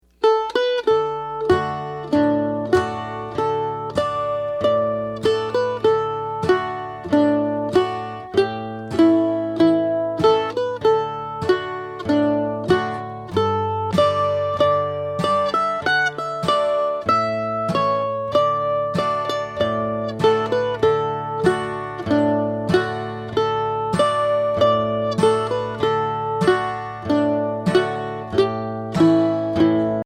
Voicing: Mandolin W